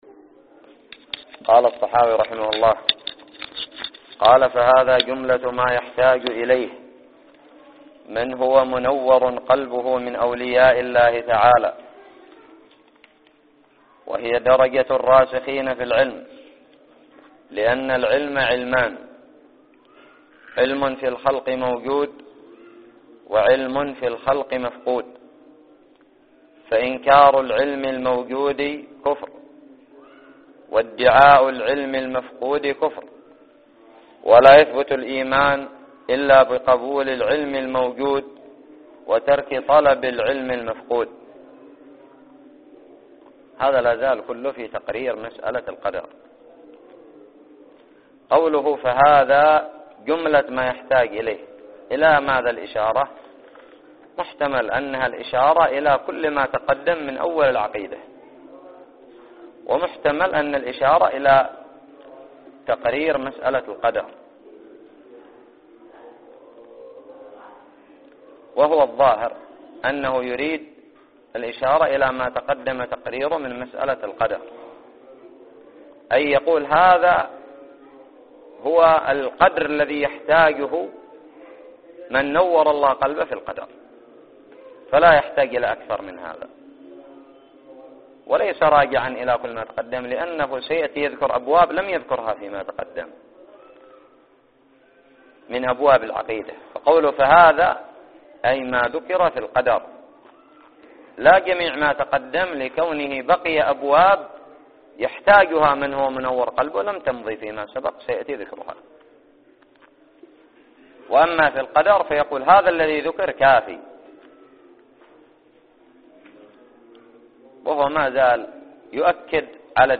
الدرس الثالث والعشرون من شرح العقيدة الطحاوية
ألقيت في دار الحديث بدماج